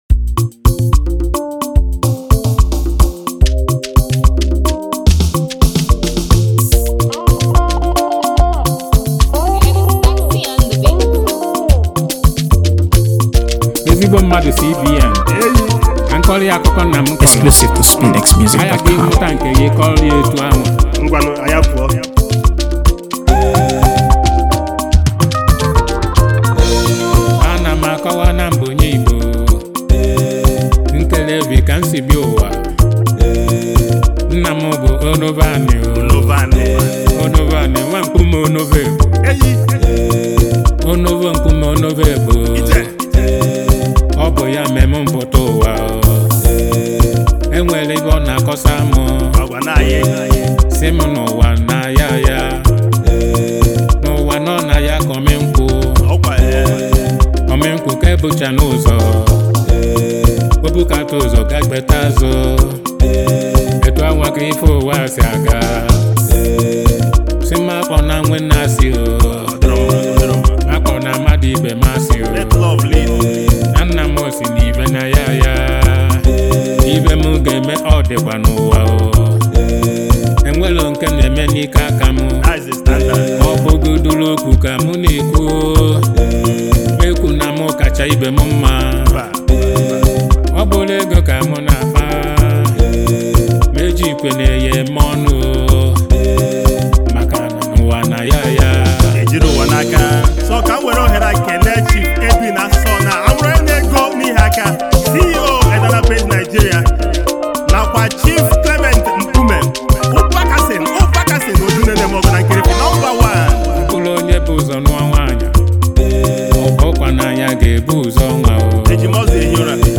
AfroBeats | AfroBeats songs
So let the soothing melodies
With its infectious rhythms and uplifting lyrics,